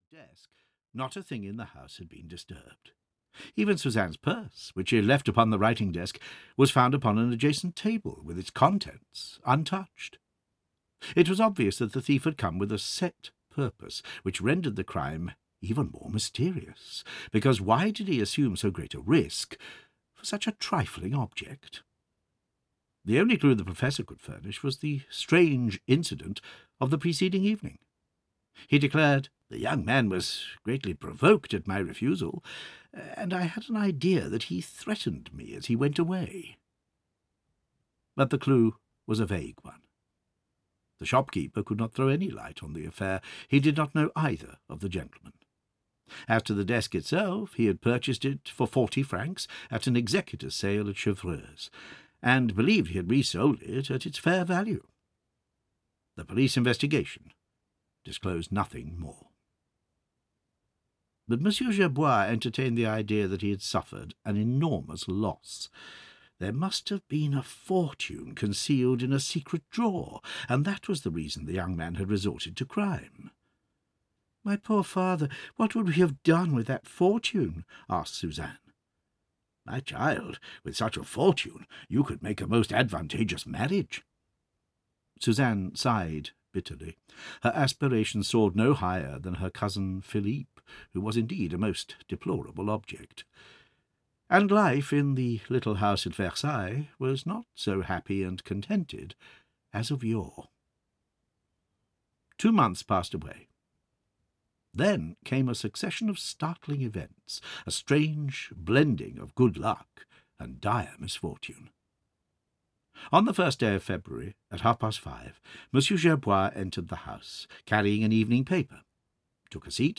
Arsène Lupin versus Herlock Sholmes (EN) audiokniha
Ukázka z knihy